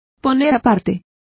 Complete with pronunciation of the translation of earmark.